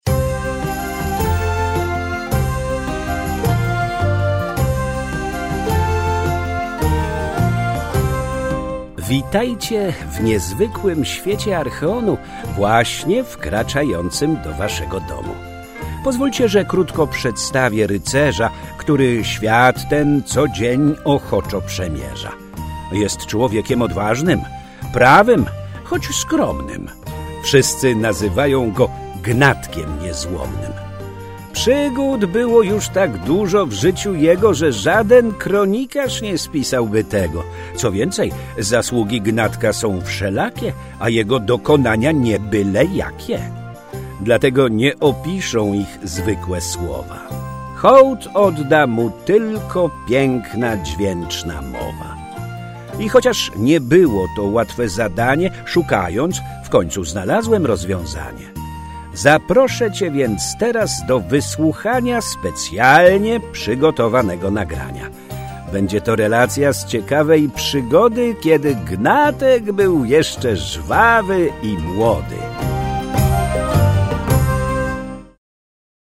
KLIKNIJ TUTAJ, BY POSŁUCHAĆ ZAPOWIEDZI :)